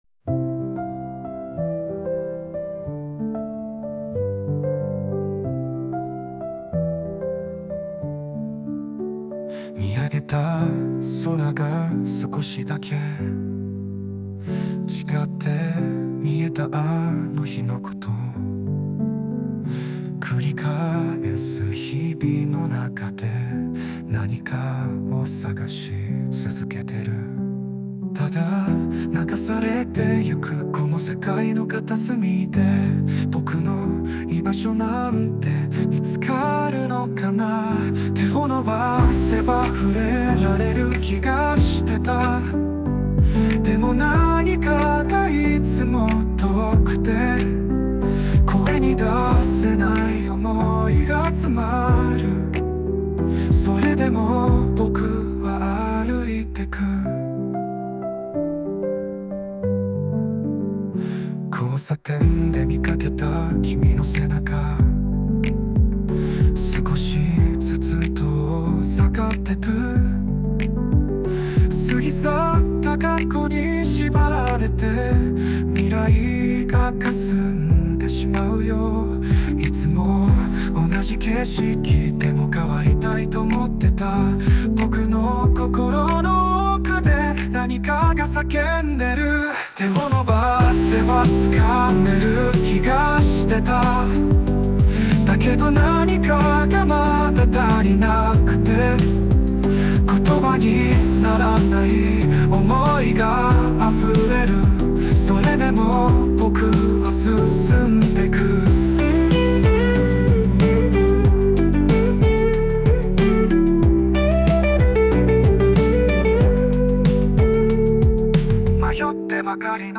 著作権フリーオリジナルBGMです。
男性ボーカル（邦楽・日本語）曲です。
男性の日本語の歌で、エモい曲を作りたくて制作しました。